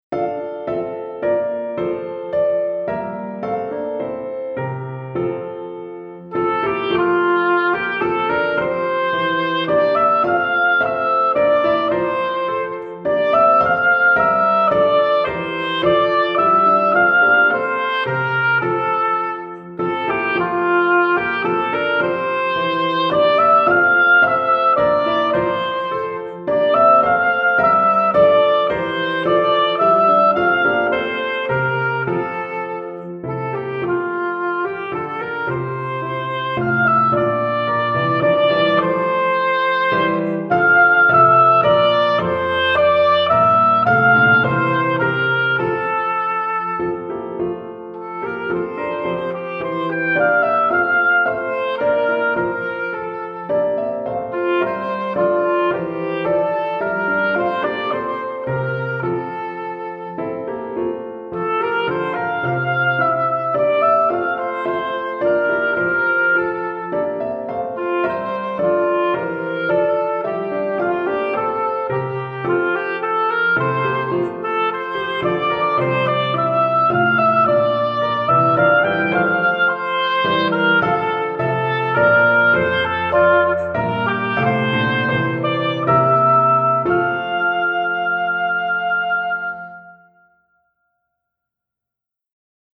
Hautbois et Piano